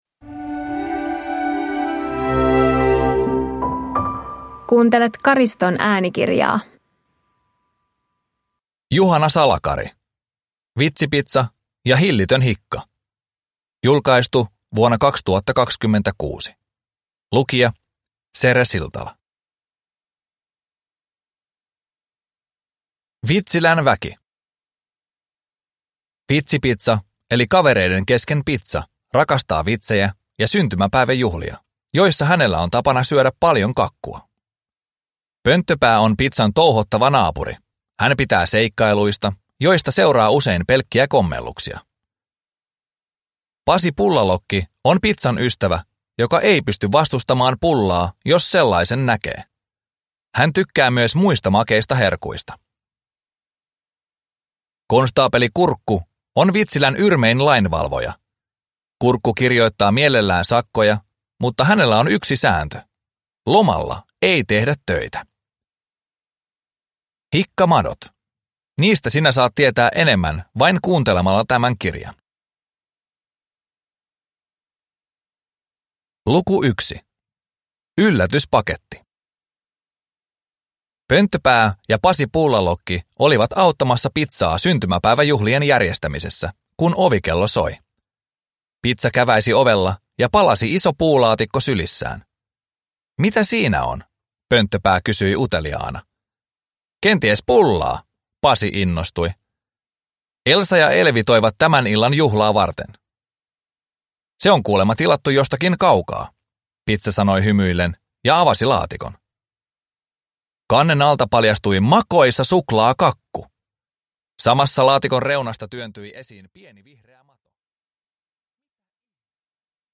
Vitsipitsa ja hillitön hikka – Ljudbok